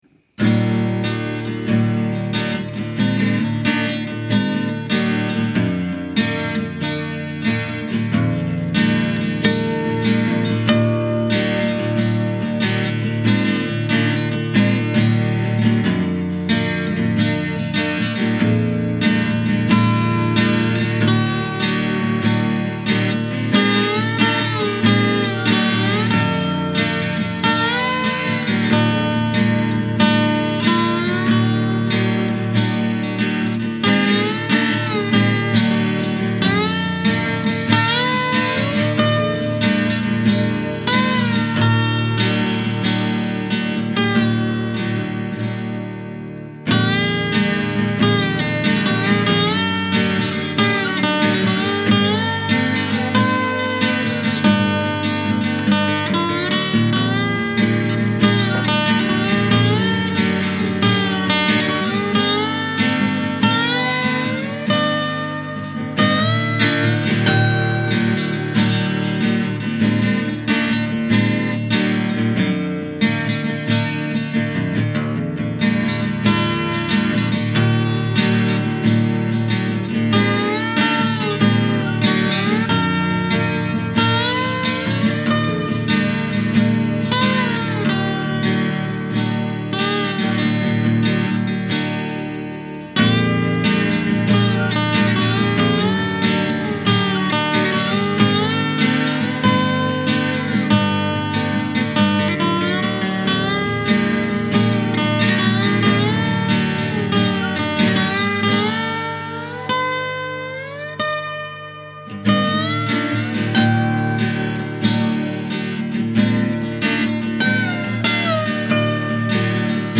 There’s no backstory to be had here – This track was driven by boredom and a desire to haul out the ol’ acoustic guitar for a change.
Recorded in Reaper with the Yeti Pro microphone; “mastered” in GarageBand. Guitars are in Open-D tuning (low to high: D A D F# A D). My slide playing is still a work in progress.